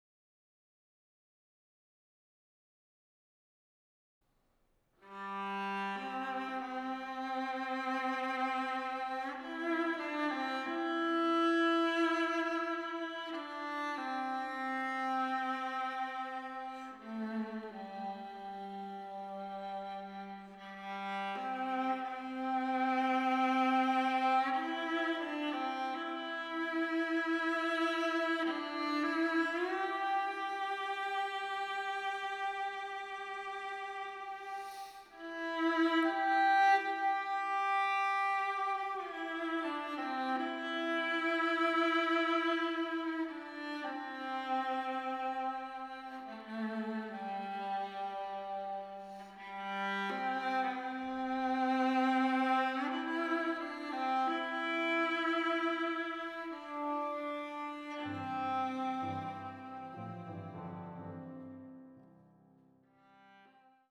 ヴィオラ